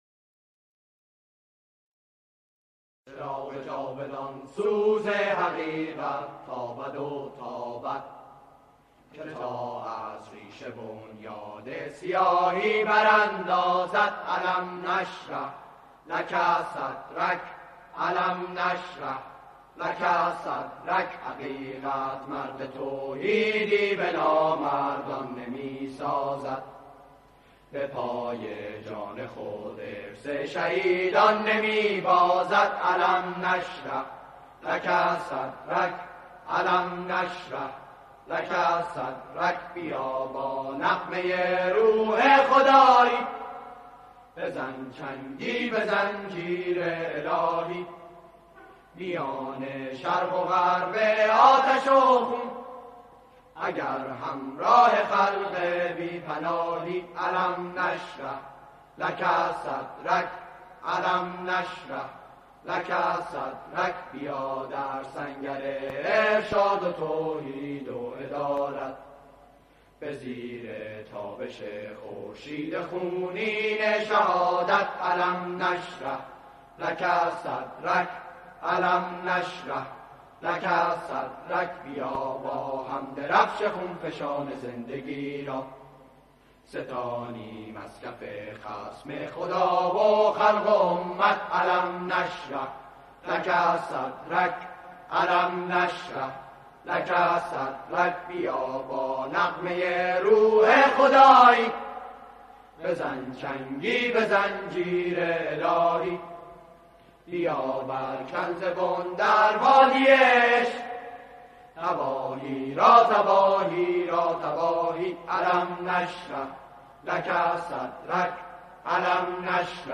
سرود قدیمی
با اجرای گروهی از همخوانان